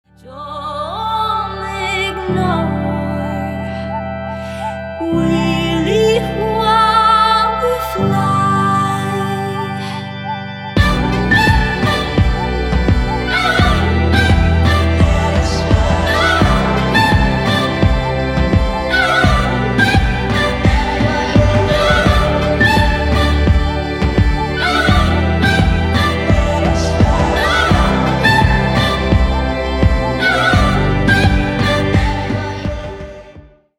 • Качество: 320, Stereo
мелодичные
Electronic
фолк
лиричные
Indie